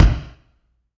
normal-hitfinish.ogg